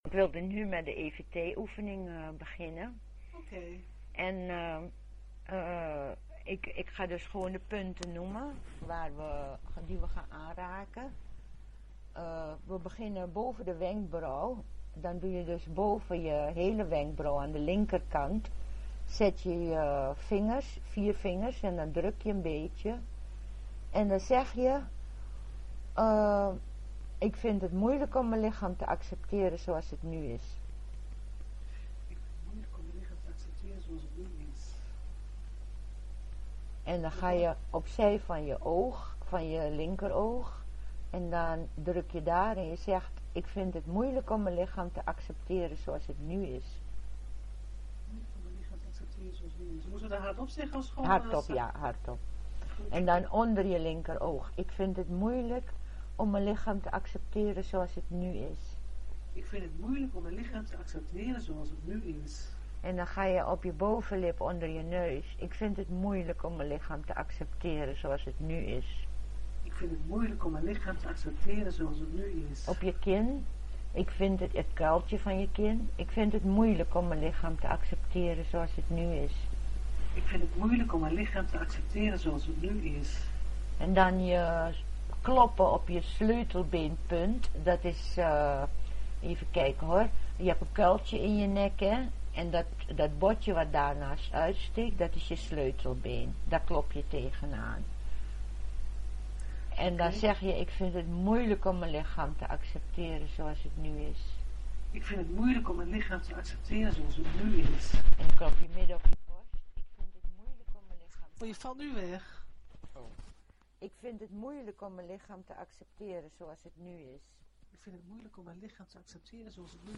de telefonische Transformator conferenties en teleseminars en webcasts
teleseminar-13okt10-b.mp3